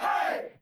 crowdHai2.wav